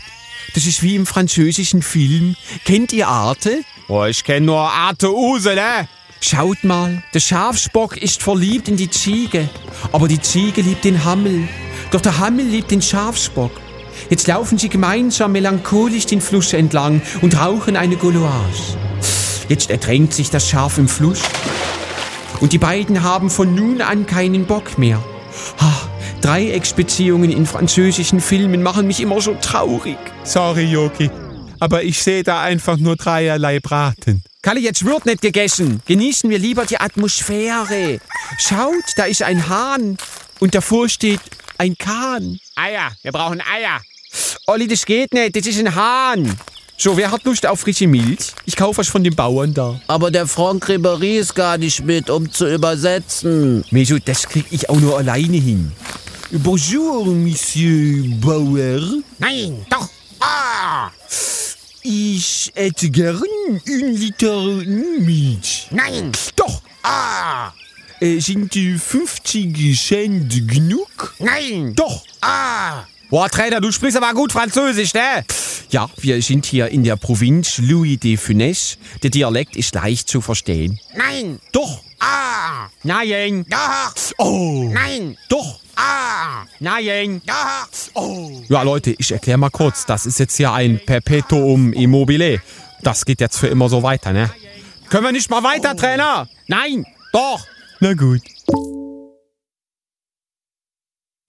Das wandelnde Ein-Man-Hörspiel, bekannt aus Ihrem Radio, erstmals auf CD.
• Bundestrainer • Europa • Europameisterschaft 2016 • Fußball • Hörbuch; Humor/Comedy • Humor • Jérôme Boateng • Jogi Löw • Live-Show • Lothar Matthäus • Nationalmannschaft • Paris • Radio • Reiner Calmund • Slang- und Dialekt-Humor • Sportmannschaften und Vereine • Stimmenimitator